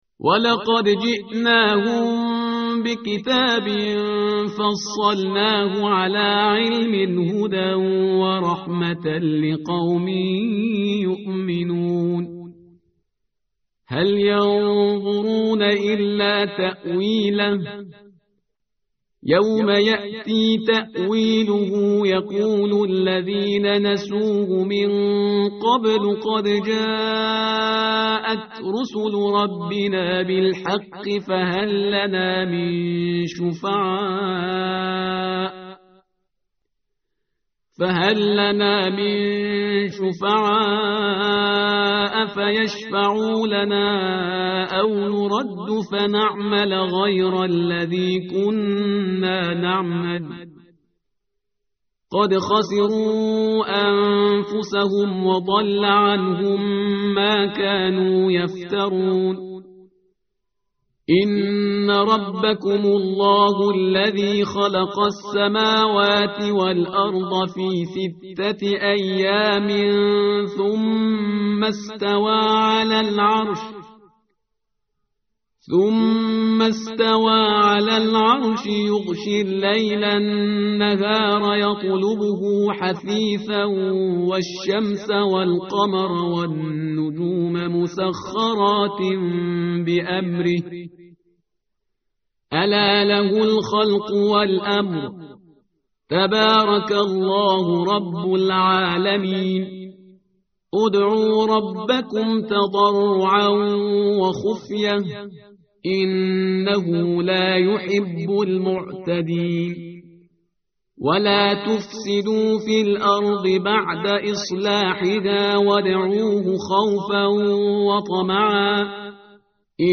متن قرآن همراه باتلاوت قرآن و ترجمه
tartil_parhizgar_page_157.mp3